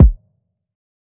KICK 8.wav